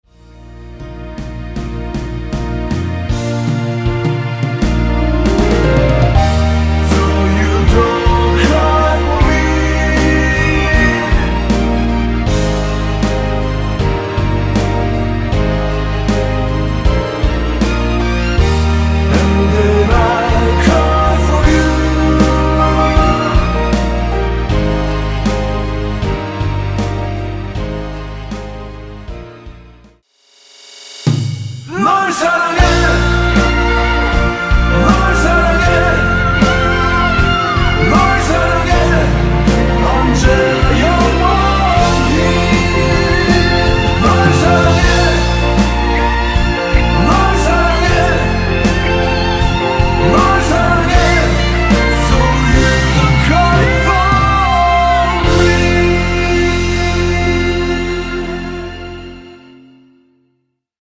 (-2) 내린코러스 포함된 MR 입니다.(아래 가사의 노란색 부분과 미리듣기참고 하세요)
Eb
◈ 곡명 옆 (-1)은 반음 내림, (+1)은 반음 올림 입니다.
앞부분30초, 뒷부분30초씩 편집해서 올려 드리고 있습니다.
중간에 음이 끈어지고 다시 나오는 이유는